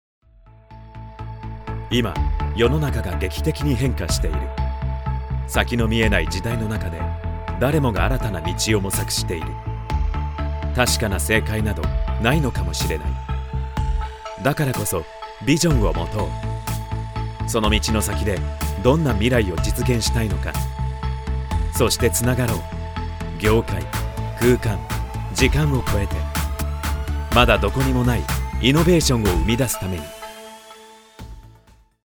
Voice Artistes- Japanese
Professional Japanese Voice oVer Artiste.